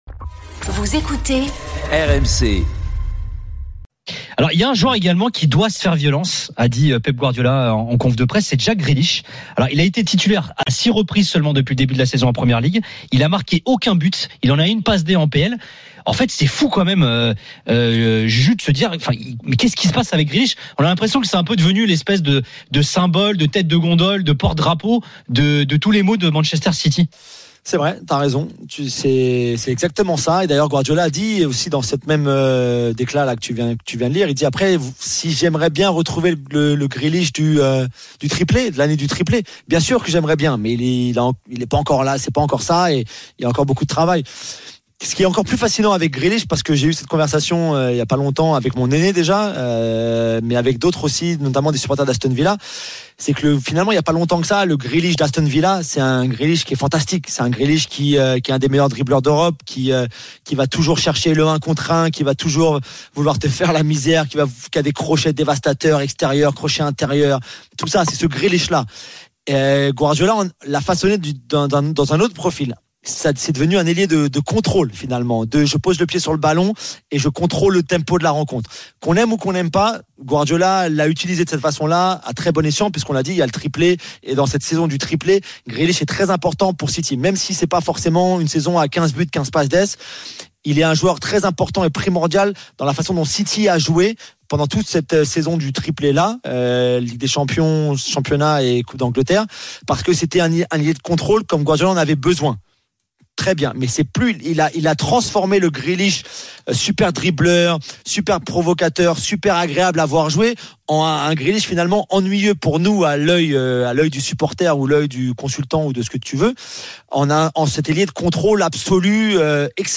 Au programme, des débats passionnés entre experts et auditeurs RMC, ainsi que de nombreux invités.